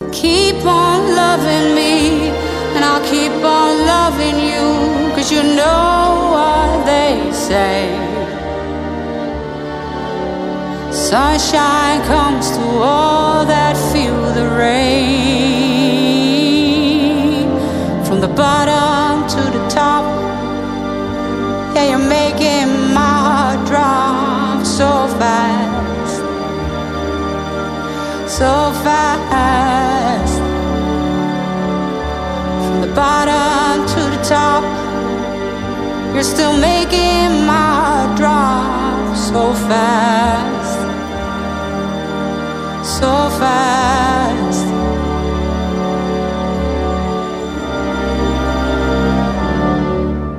Pop, Orchestral Pop